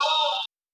Vox
TS - CHANT (2).wav